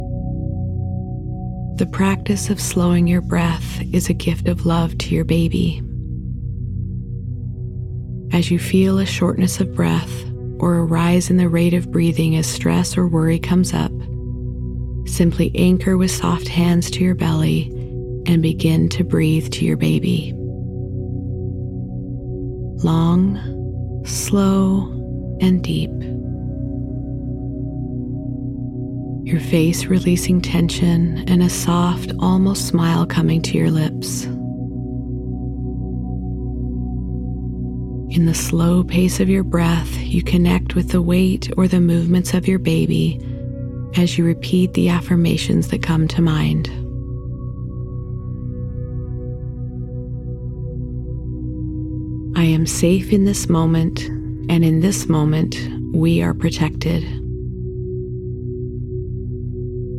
In this pregnancy meditation, we will explore choice with the thoughts you let into your mind each day.